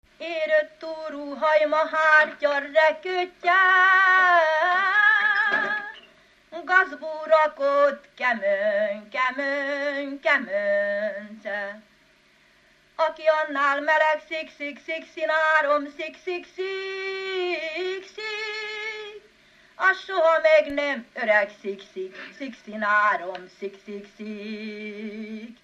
Dunántúl - Tolna vm. - Alsónyék
ének
Stílus: 1.2. Ereszkedő pásztordalok
Szótagszám: 8.8.8.8
Kadencia: 4 (b3) 4 1